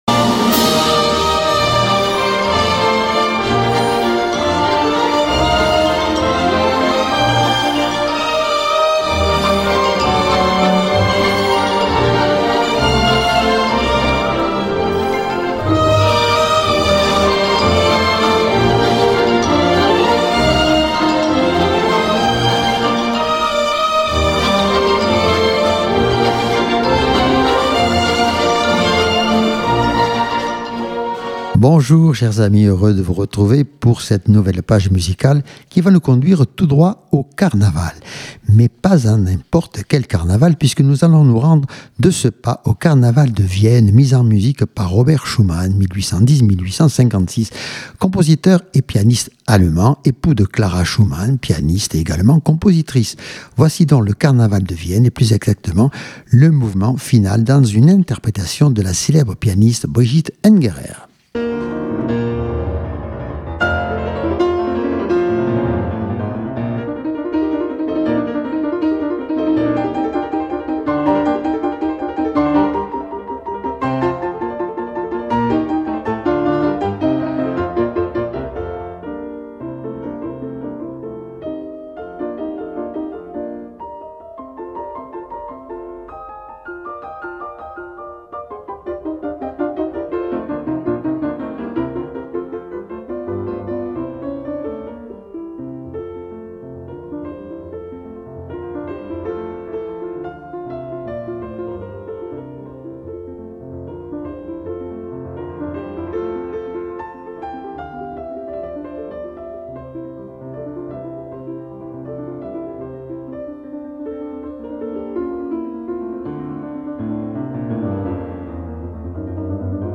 une émission Musicale